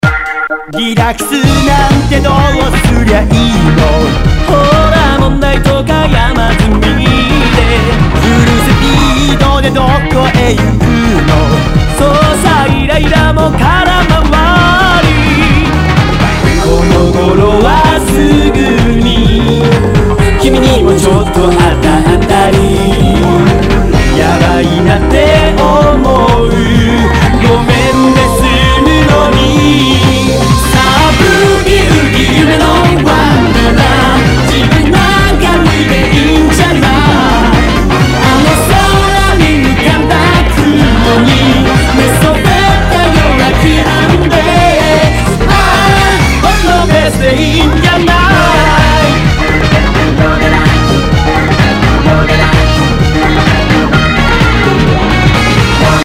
HOUSE/TECHNO/ELECTRO
ナイス！ディスコ・ヴォーカル・ハウス！
全体にチリノイズが入ります